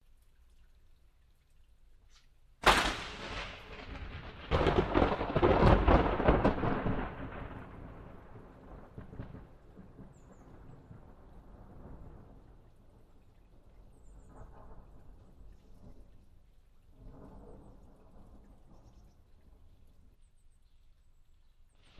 Гром без дождя